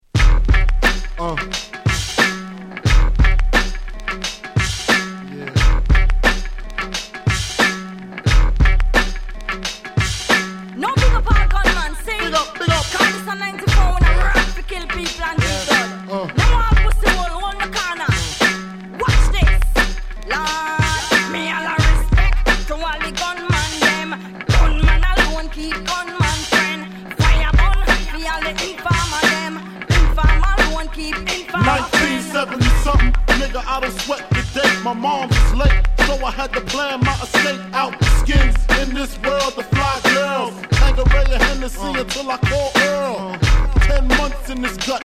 94’ Super Hit 90's Hip Hop LP !!
90's Boom Bap